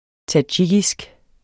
tadsjikisk adjektiv Bøjning -, -e Udtale [ taˈdɕigisg ] Betydninger 1. fra det centralasiatiske land Tadsjikistan; vedr.